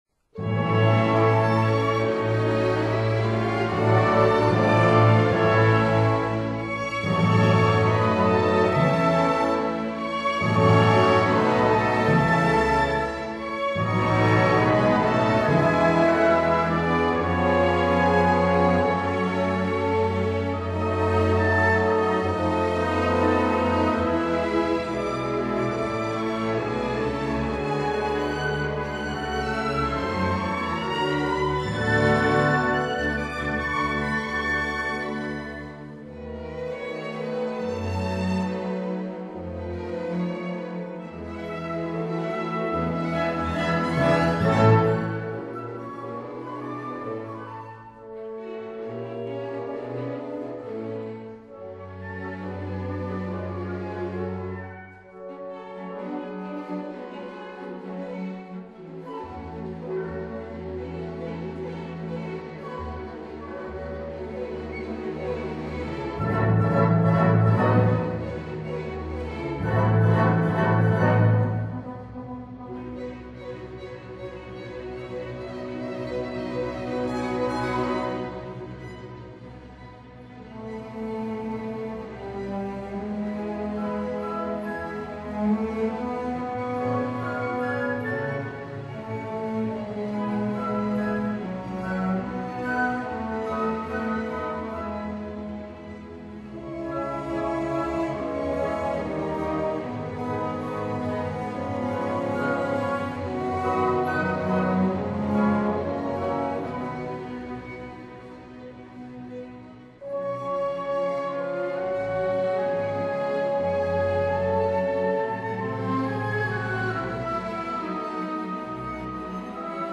for orchestra in A major